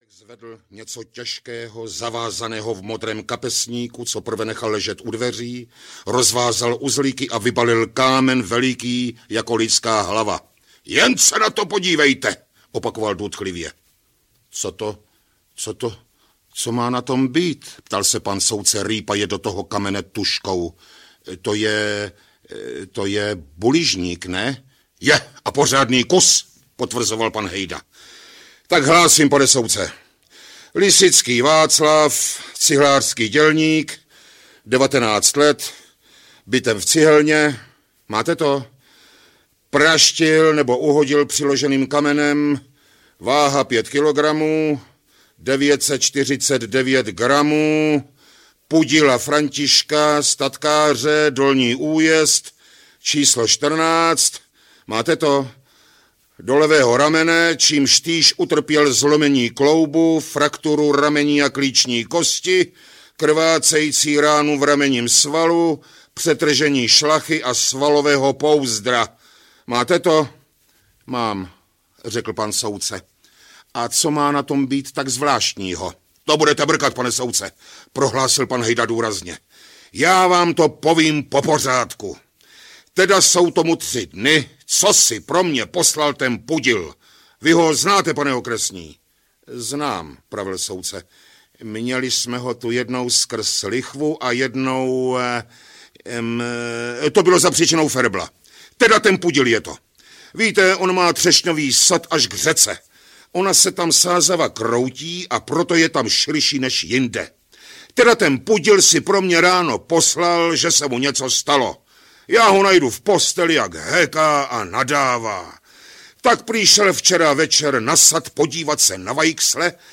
Z díla velikána české i světové literatury audiokniha
Ukázka z knihy